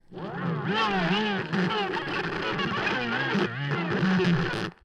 Здесь вы найдете характерные потрескивания, мягкие шипения и другие аутентичные звуки аналоговой эпохи.
Звуки винила: заевшая пластинка